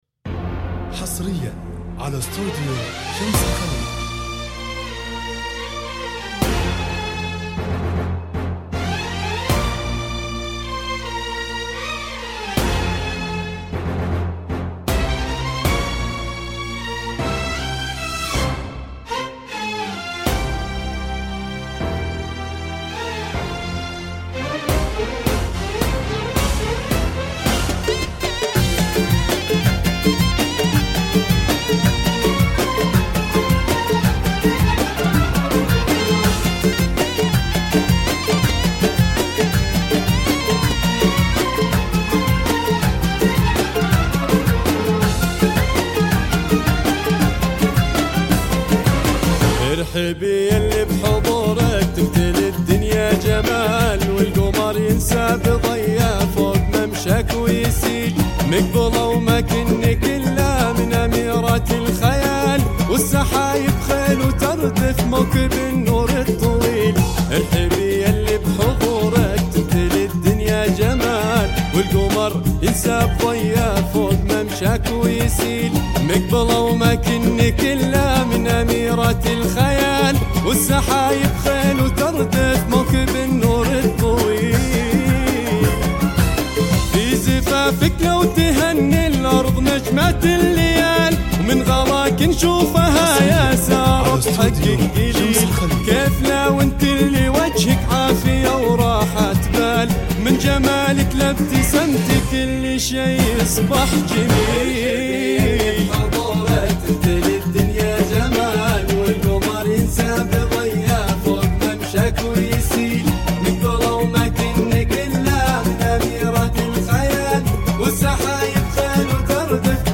زفات موسيقى – زفات كوشة